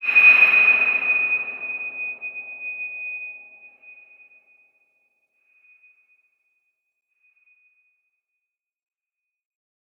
X_BasicBells-D#5-ff.wav